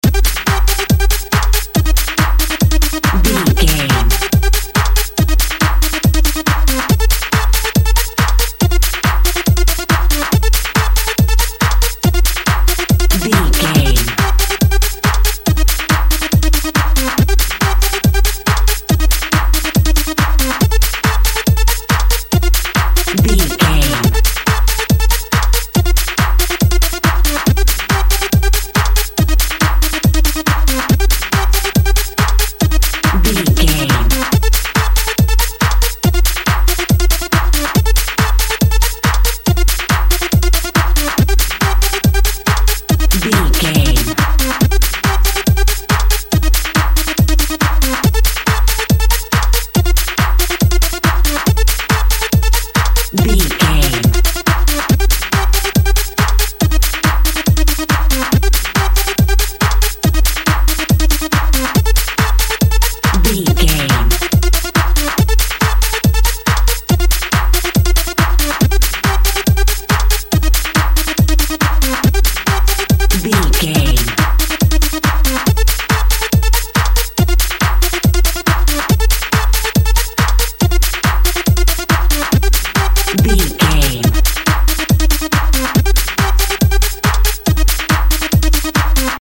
Dance Techno.
Aeolian/Minor
driving
energetic
futuristic
hypnotic
drums
synthesiser
synth lead
synth bass